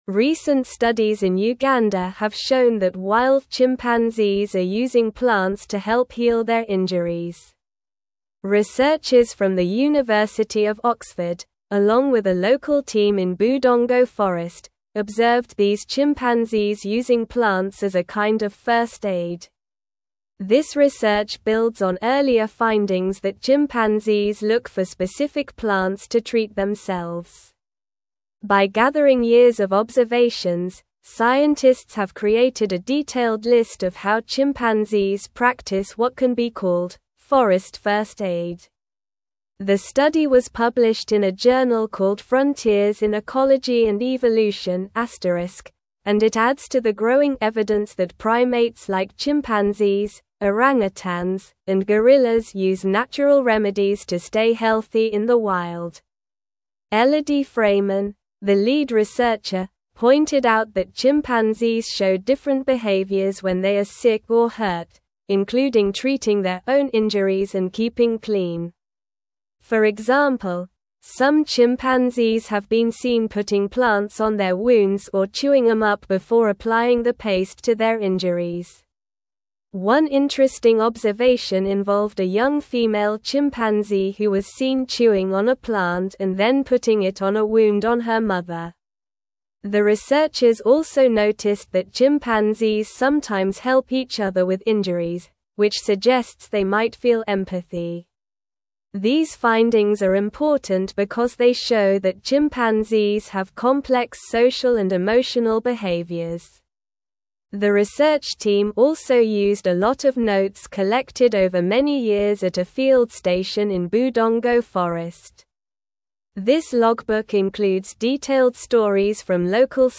Slow
English-Newsroom-Upper-Intermediate-SLOW-Reading-Chimpanzees-Use-Medicinal-Plants-for-Self-Care-in-Uganda.mp3